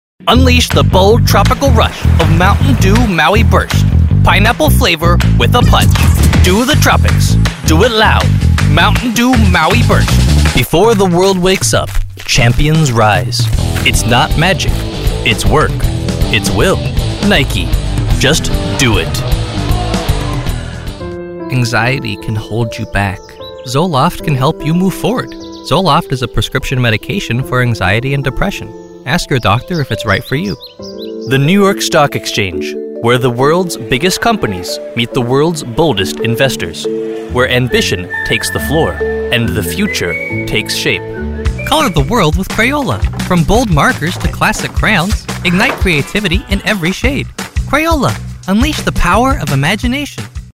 With the flexibility to record in my own fully equipped home studio, I’m committed to bringing the right voice to your project.
Commercial Demos
Commercial-Demos.mp3